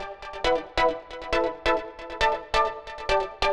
Index of /musicradar/future-rave-samples/136bpm
FR_Minee_136-E.wav